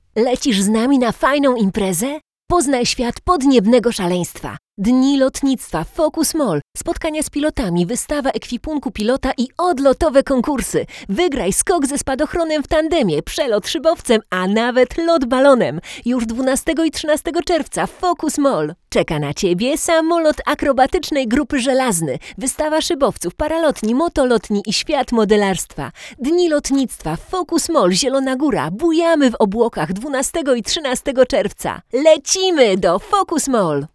Nagranie wokal żeński
Nowa 67ka praktycznie nie szumi.